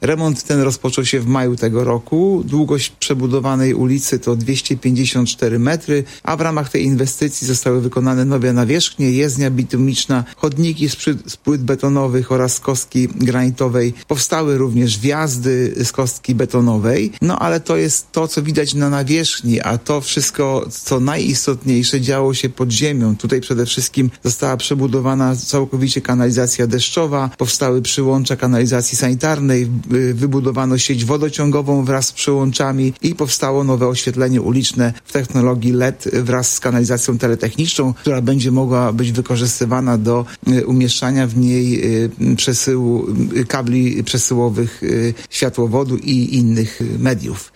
Mówił we wtorek (09.12) w Radiu 5 Artur Urbański, zastępca prezydenta Ełku.